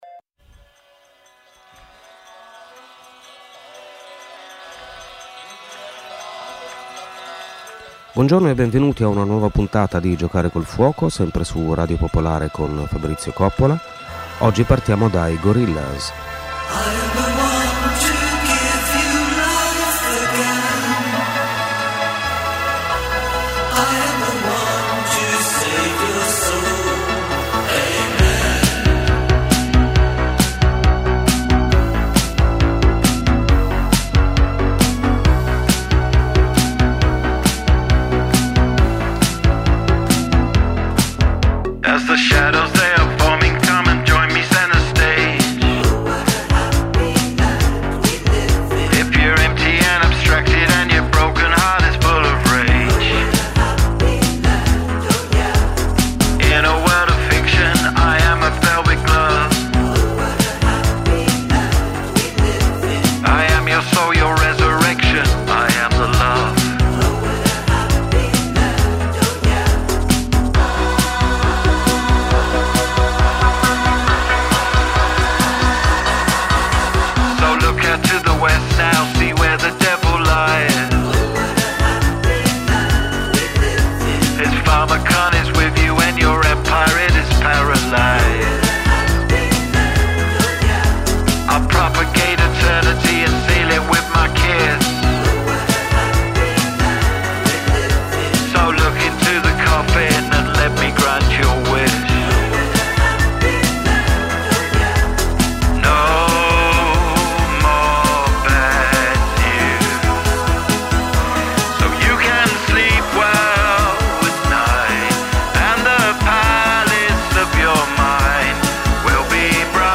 Memoir e saggi, fiction e non fiction, poesia (moltissima poesia), musica classica, folk, pop e r’n’r, mescolati insieme per provare a rimettere a fuoco la centralità dell’esperienza umana e del racconto che siamo in grado di farne.